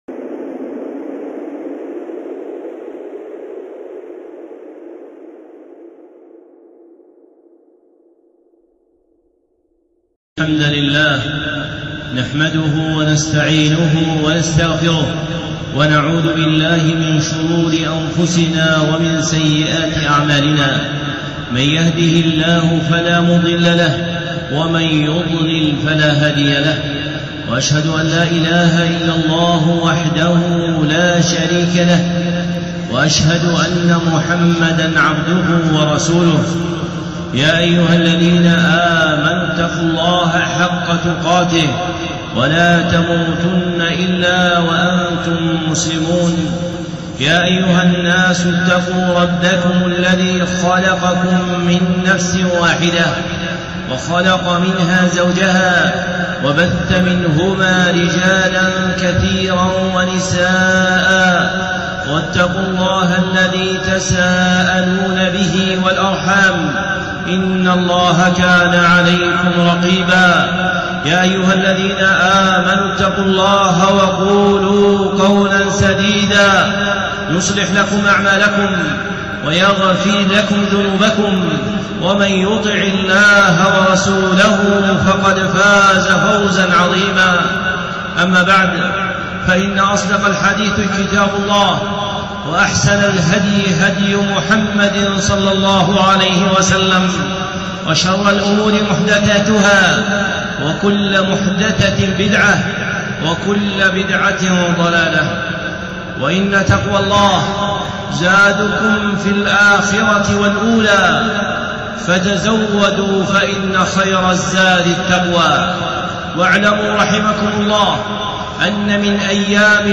خطبة (الأقصى في عاشوراء) الشيخ صالح العصيمي
الخطب المنبرية